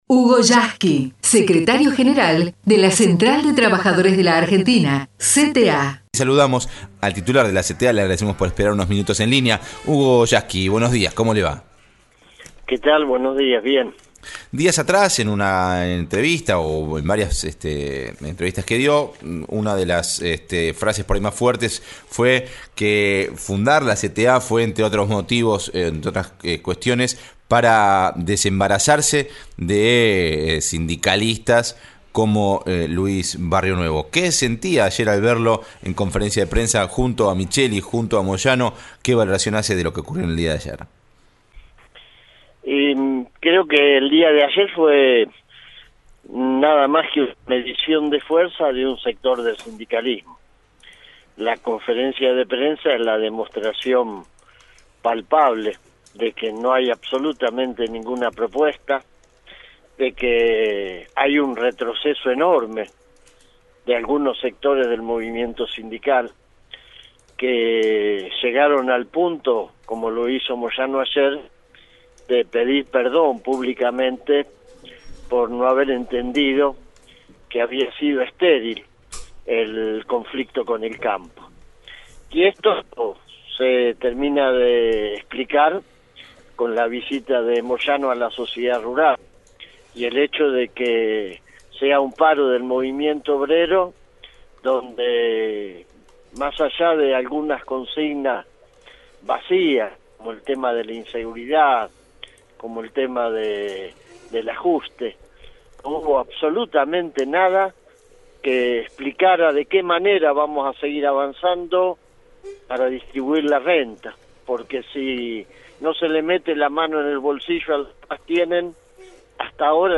Hugo Yasky fue entrevistado por Roberto Caballero en su programa de Radio Nacional, Mañana es hoy. Allí habló sobre el paro del 10 de abril.